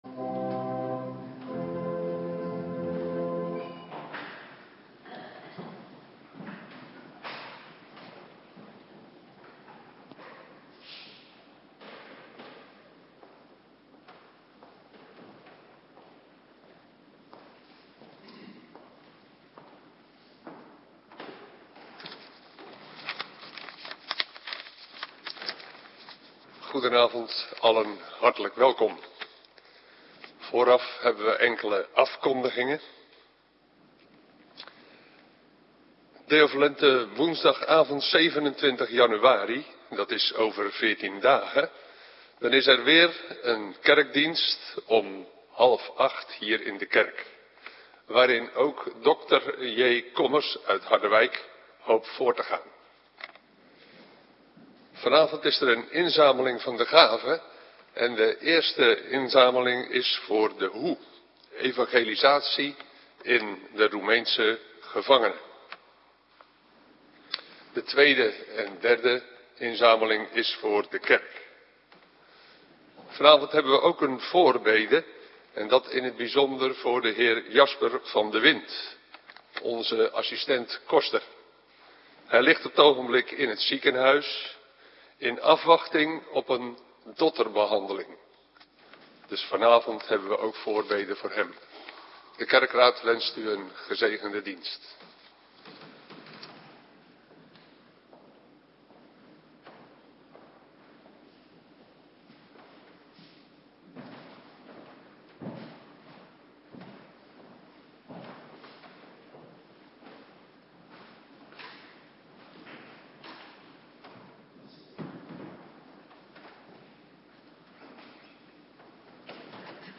Avonddienst Bijbellezing - Alle wijken
Locatie: Hervormde Gemeente Waarder